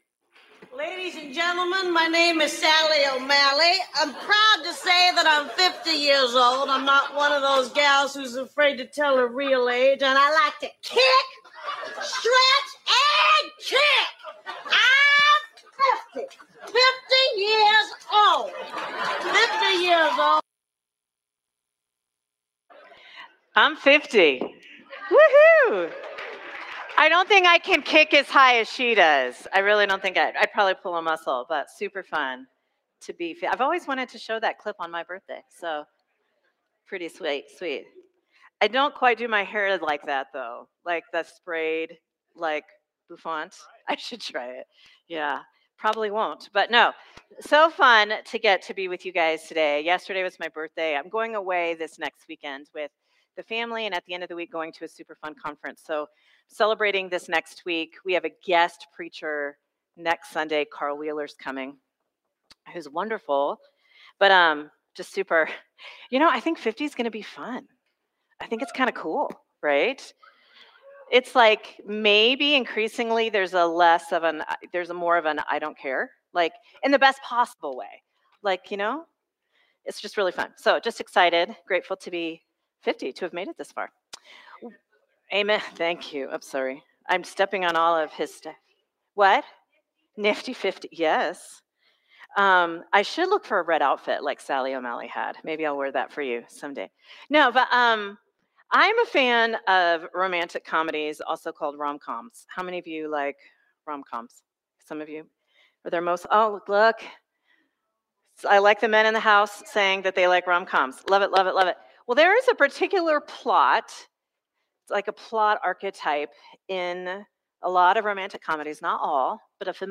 Sermon from Celebration Community Church on October 12, 2025